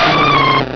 cries
delibird.wav